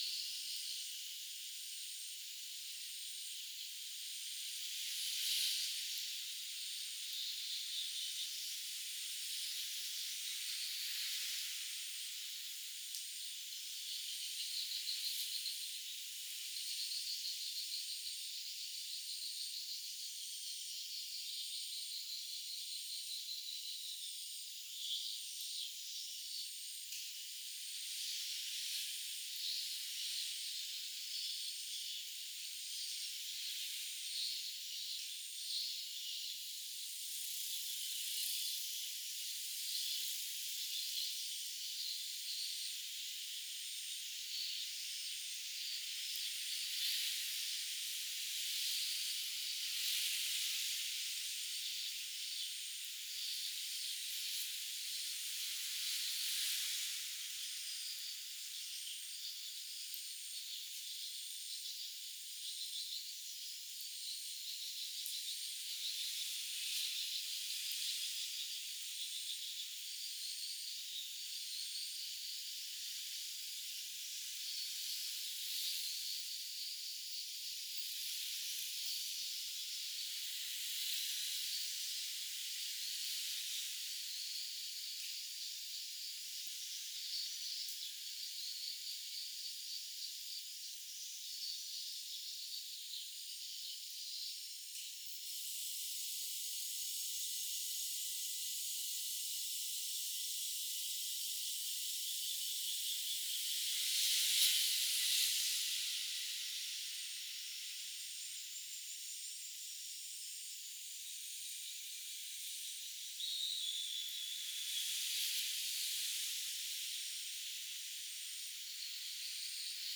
Demonstration soundscapes
anthropophony
biophony